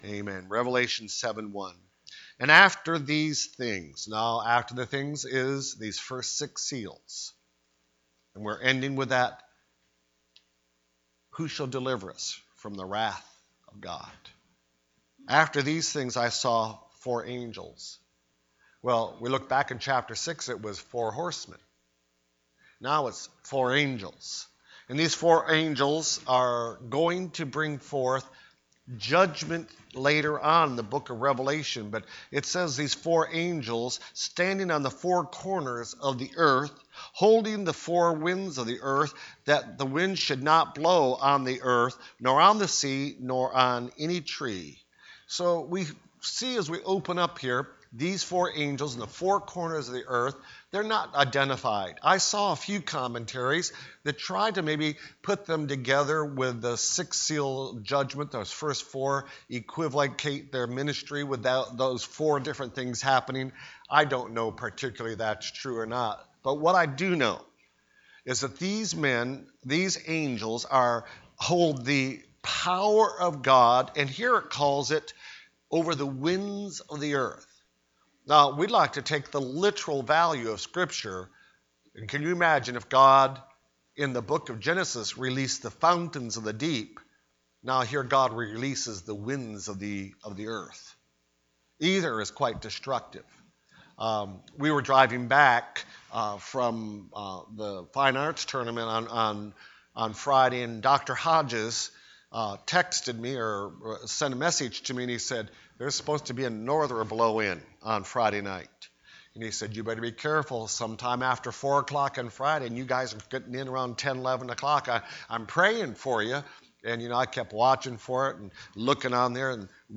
The Revelation Service Type: Sunday Evening Preacher